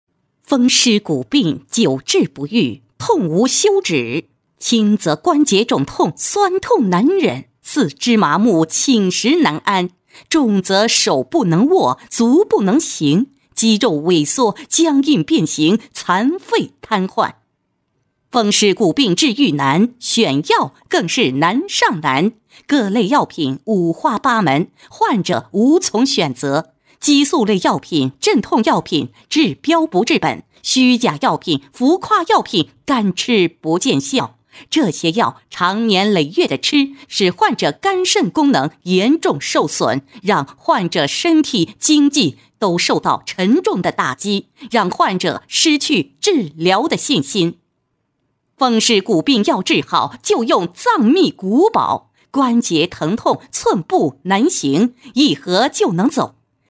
专题片宣传片配音作品在线试听-优音配音网
女声配音